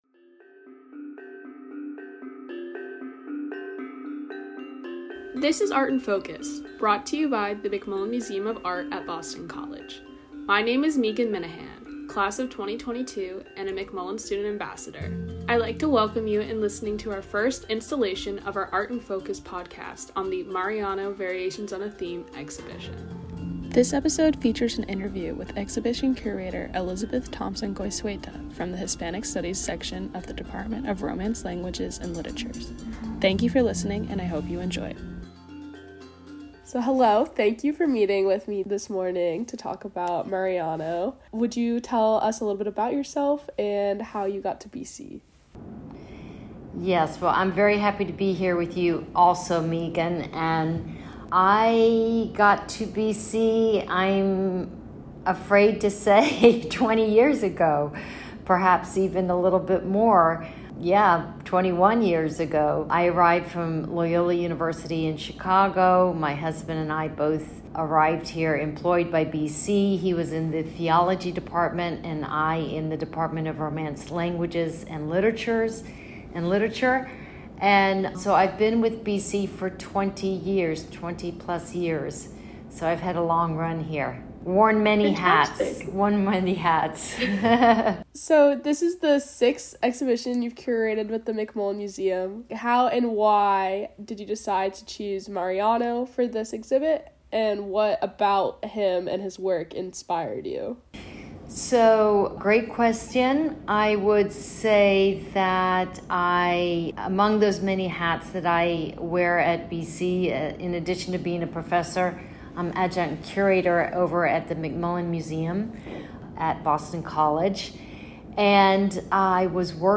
The McMullen Student Ambassadors are pleased to present Art in Focus, featuring an informal discussion between professors from various academic departments at Boston College. With each new episode, we aim to uncover a unique perspective on the works on display, informed by research and methodologies in areas of study across the University.